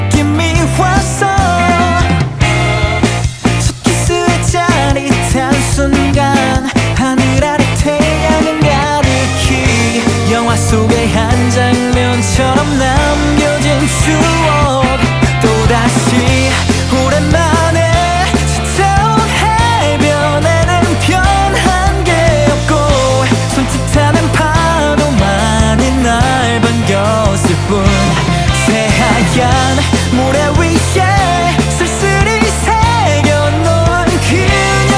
2025-07-06 Жанр: Рок Длительность